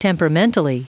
Prononciation du mot temperamentally en anglais (fichier audio)
Prononciation du mot : temperamentally